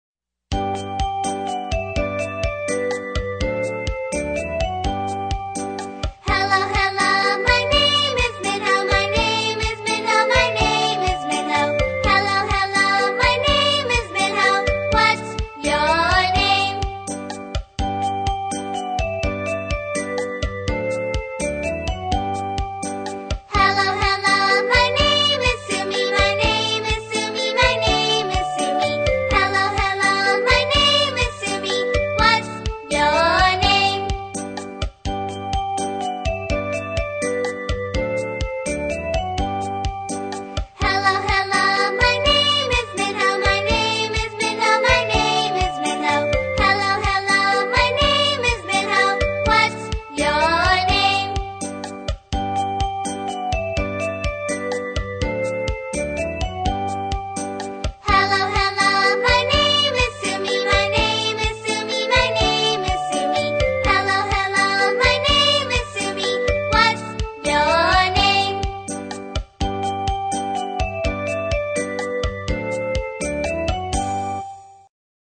在线英语听力室英语儿歌274首 第255期:What's your name的听力文件下载,收录了274首发音地道纯正，音乐节奏活泼动人的英文儿歌，从小培养对英语的爱好，为以后萌娃学习更多的英语知识，打下坚实的基础。